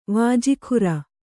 ♪ vāji khura